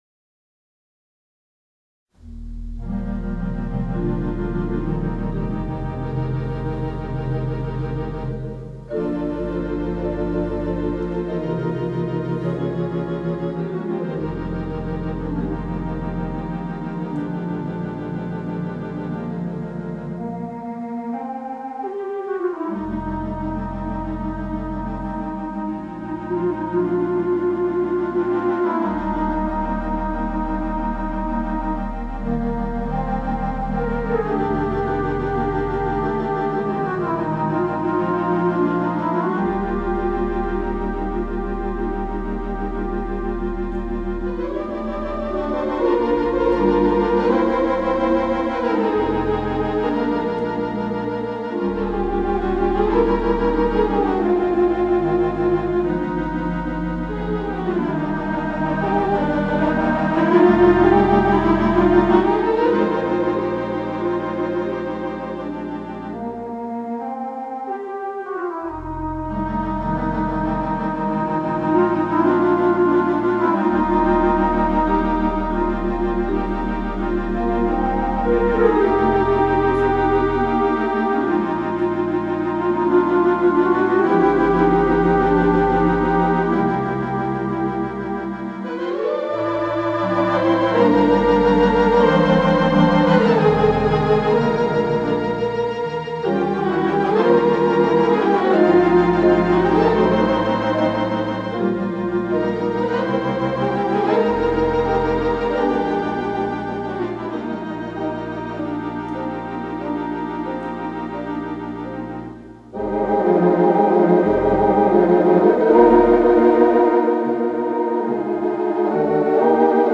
Digital Theatre Organ
The Second Concert